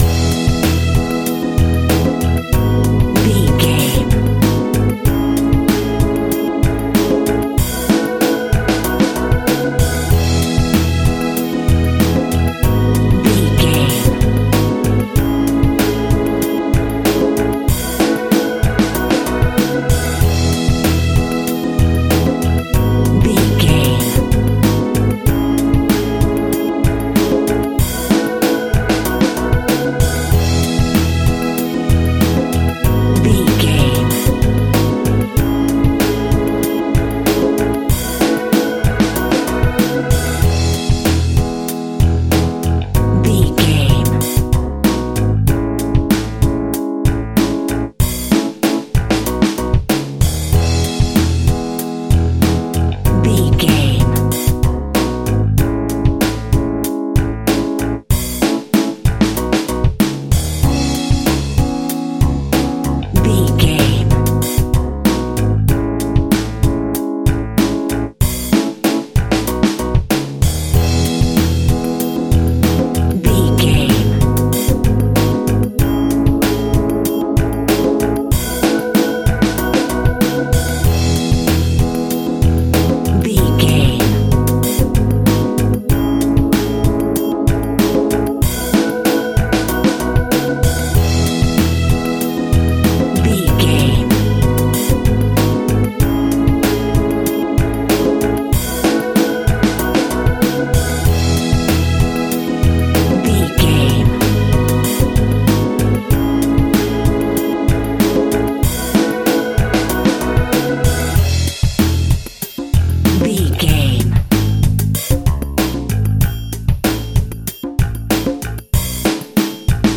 Ionian/Major
R+B
blues
jazz
Funk
smooth soul
Motown sound
northern soul
drums
bass guitar
electric guitar
piano
hammond organ